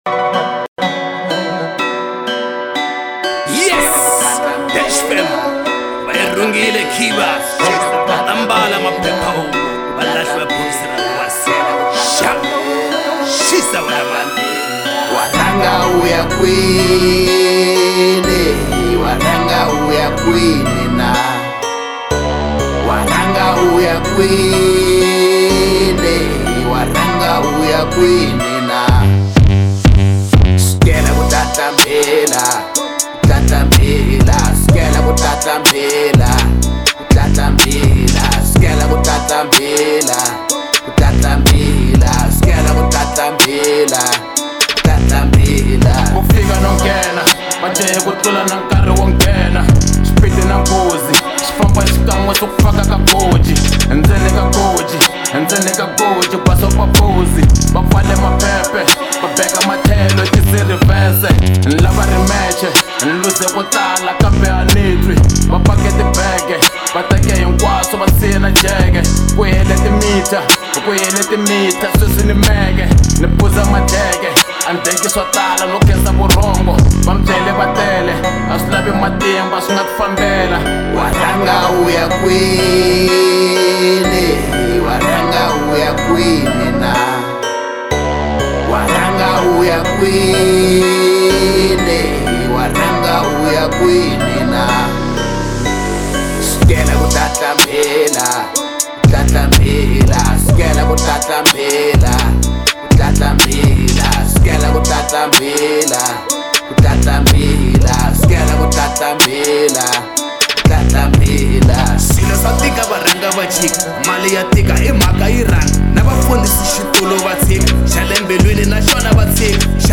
03:09 Genre : Hip Hop Size